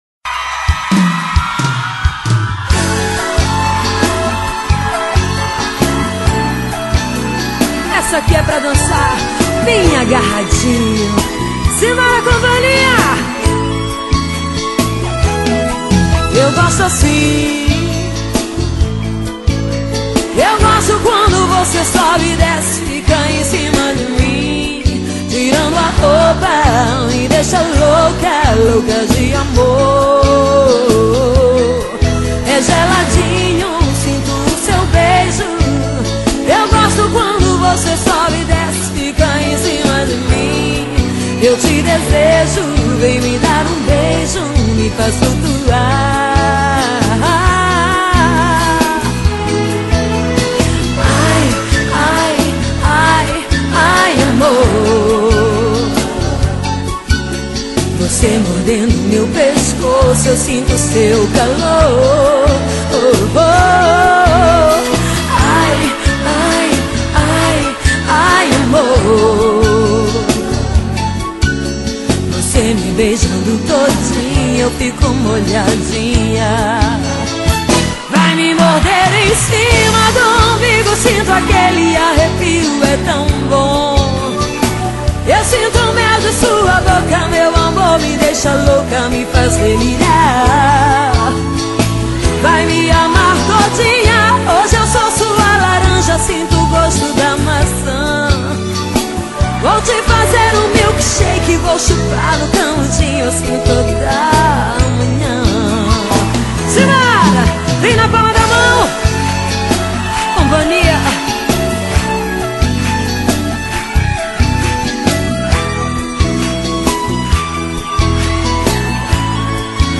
Forro